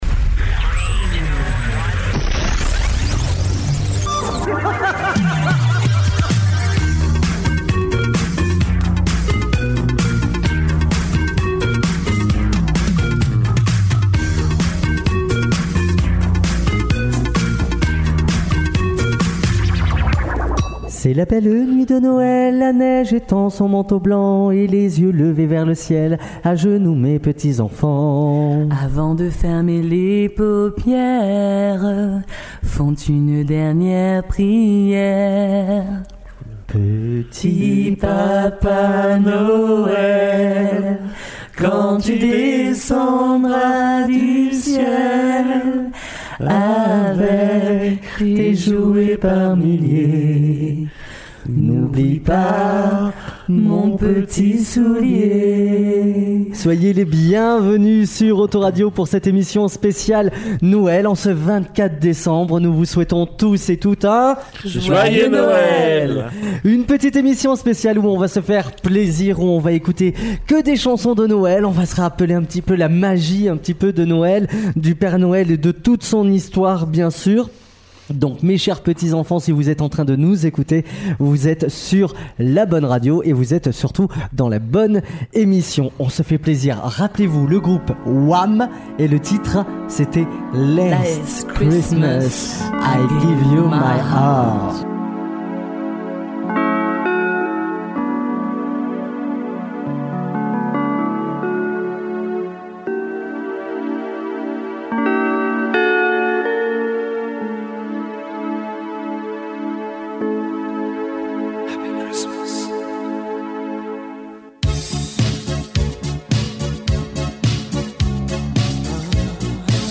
Une bande d’animateurs radio complètement fous…
Émission Come Back, spéciale Noël